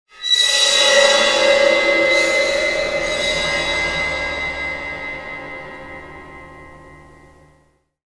Звуки скримера, неожиданности